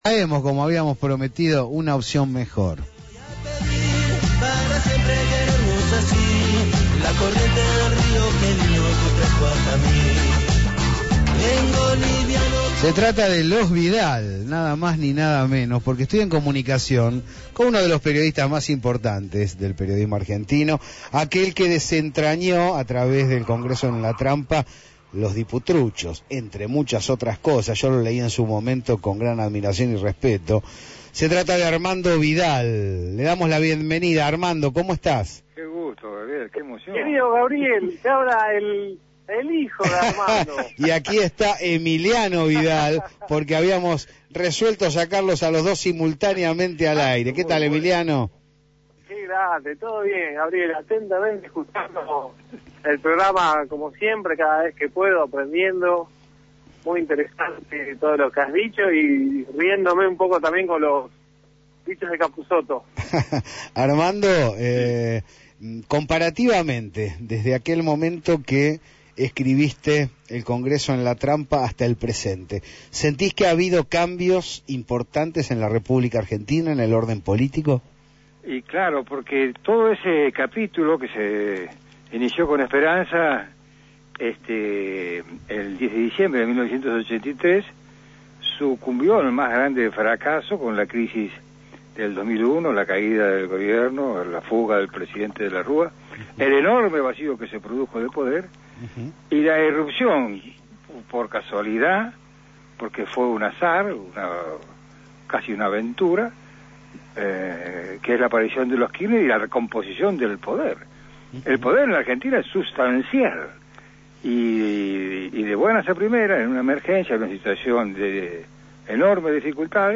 Ambos estuvieron en La Señal, en una charla imperdible.
Una charla con varias facetas, como la política internacional.